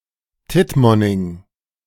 Tittmoning (German: [ˈtɪtmɔnɪŋ] (audio speaker icon
De-Tittmoning.ogg